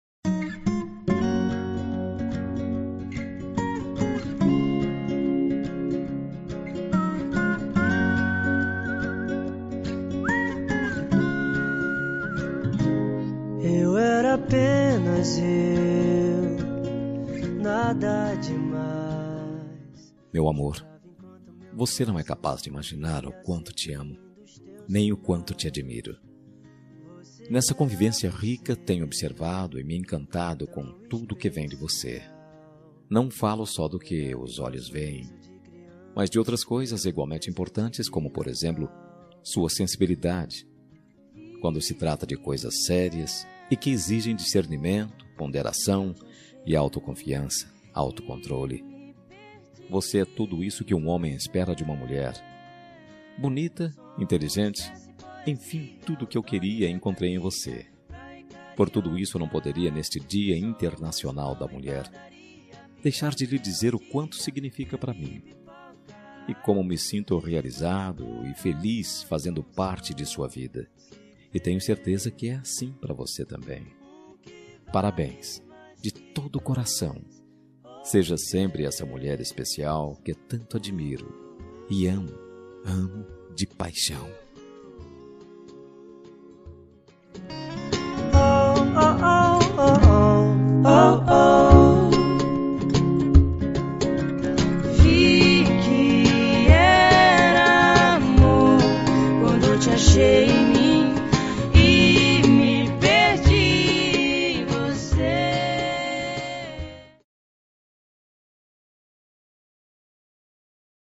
Dia das Mulheres Para Namorada – Voz Masculina – Cód: 53030